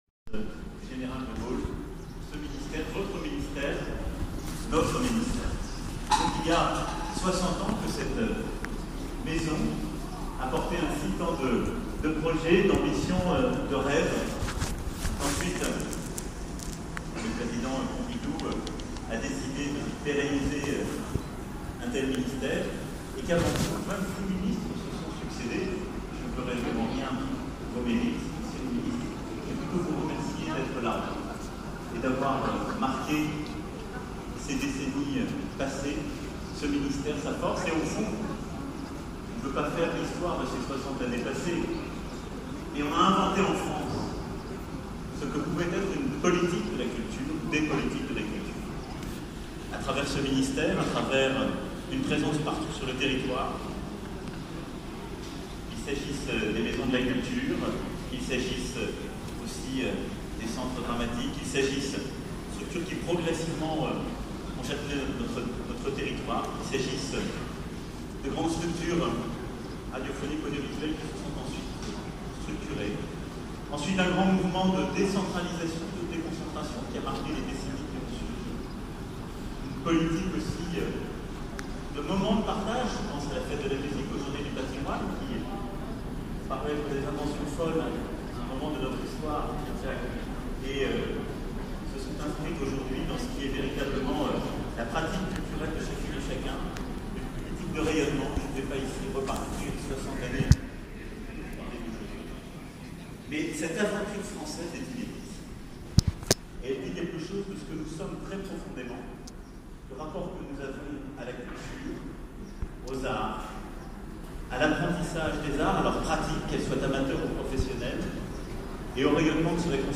Discours Emmanuel Macron pour les 60 ans du ministère - la CGT Culture